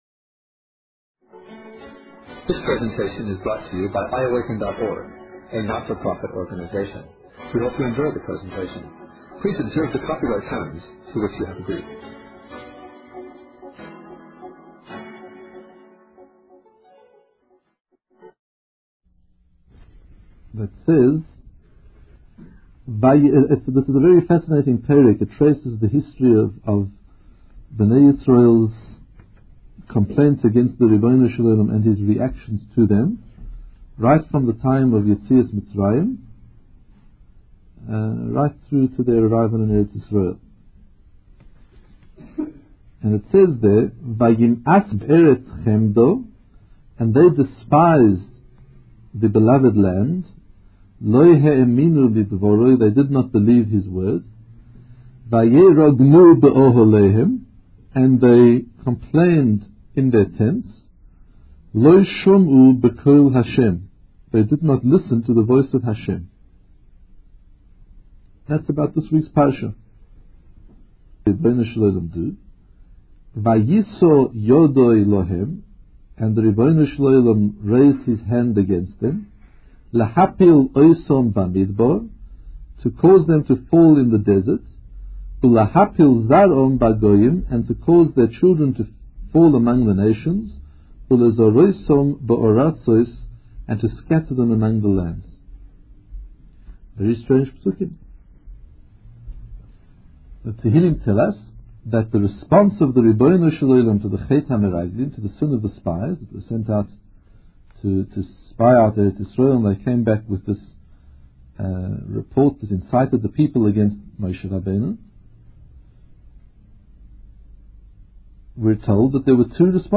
Shlach_Lecha_1984_shiur.mp3